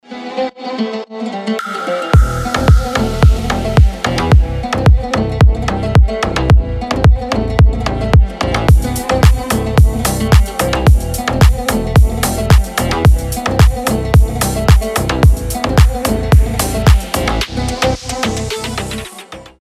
• Качество: 320, Stereo
deep house
без слов
басы
восточные
Восточный дип хаус плюс трэп